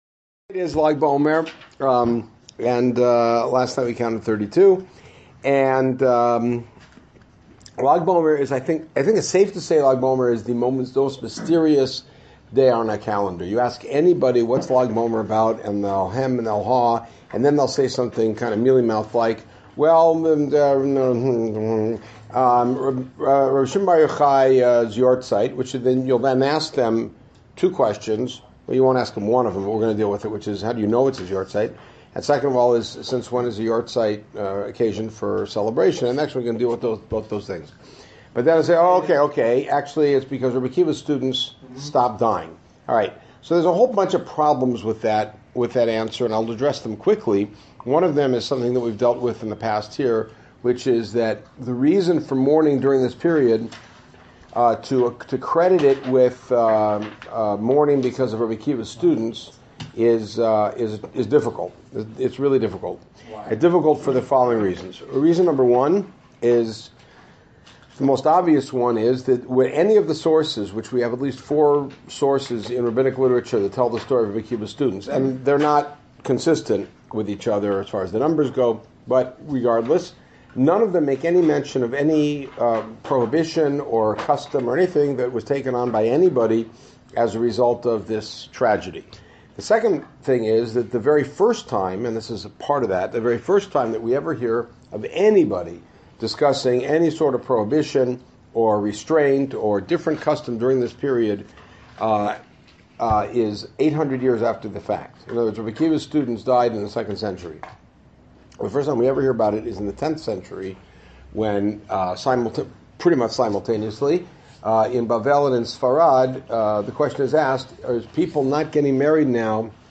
This shiur is based on the research o